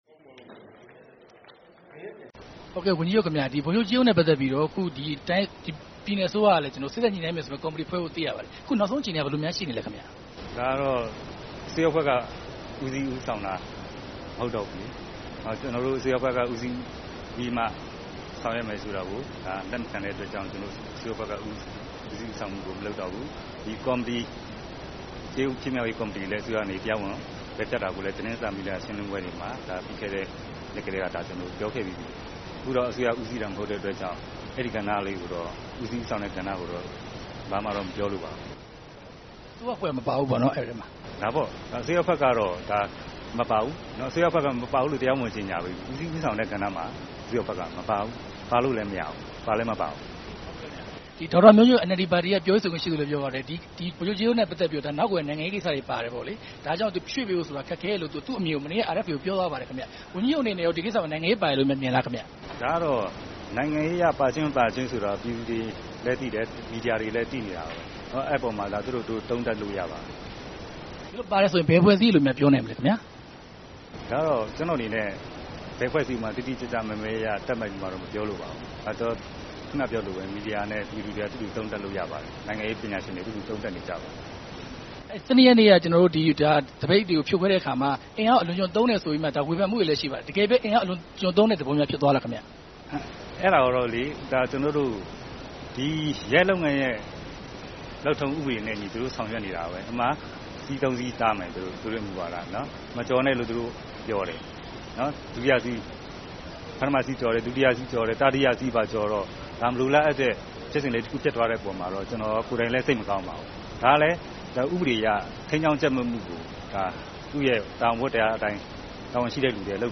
ကယားဝန်ကြီးချုပ်နဲ့ မေးမြန်းချက်